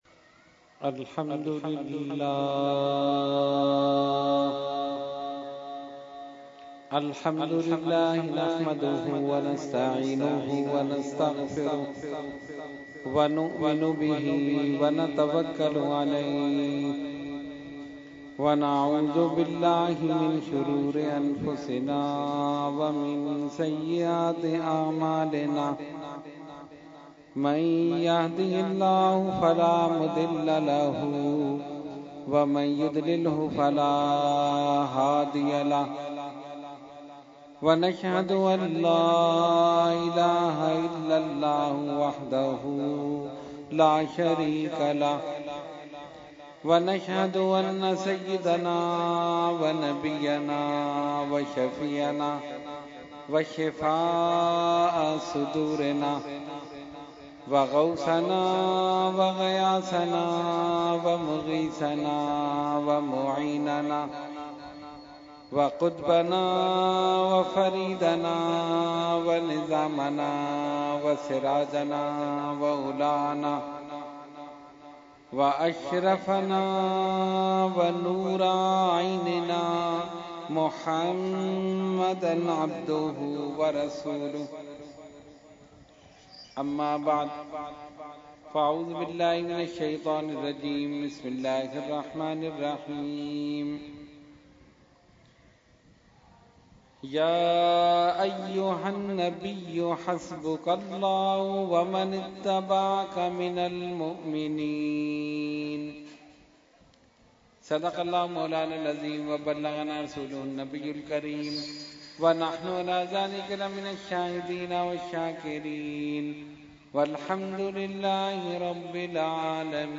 Category : Speech | Language : UrduEvent : Muharram 2018